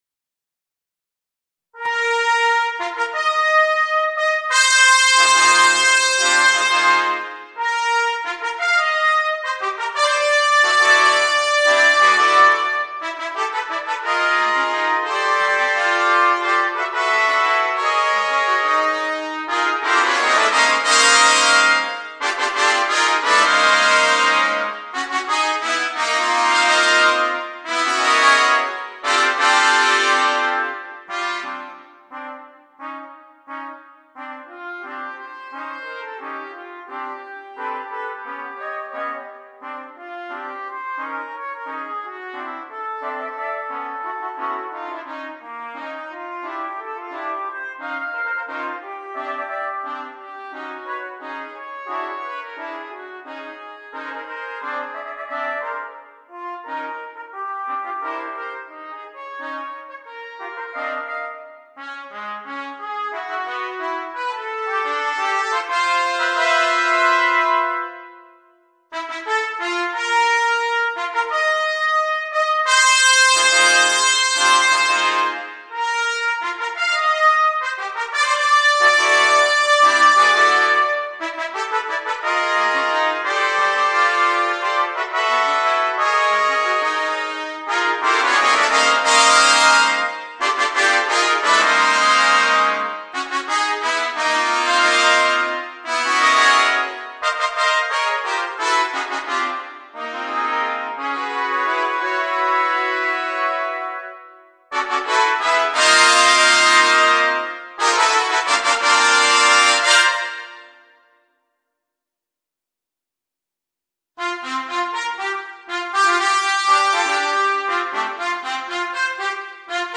Voicing: Trumpet Choir